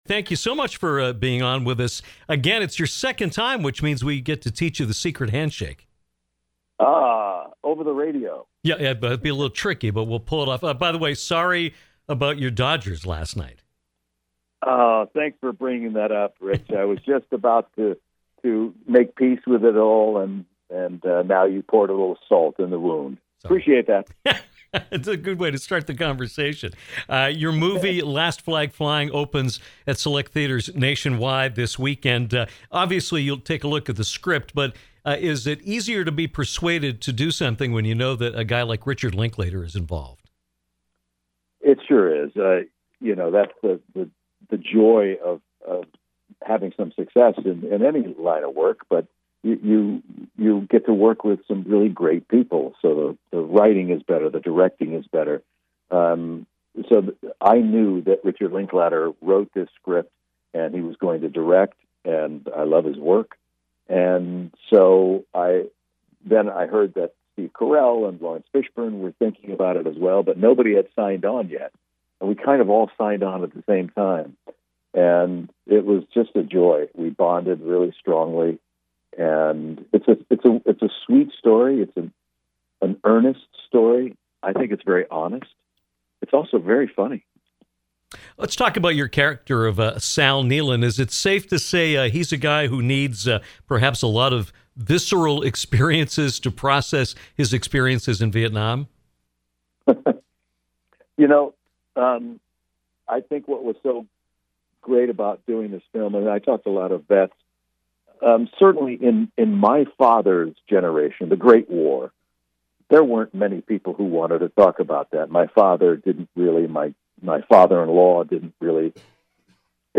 Actor Bryan Cranston returned to Downtown to talk with us about his new film, “Last Flag Flying”, his upcoming London stage appearance in the National Theatre’s production of “Network”, and even played a little Bryan Cranston trivia with us.